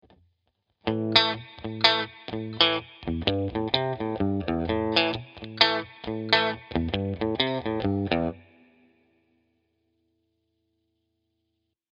To illustrate this we’ll start with a basic bluesrock riff.
As you can hear and see, there’s nothing really advanced to this riff (expect for the syncopated rhythm in the first bar).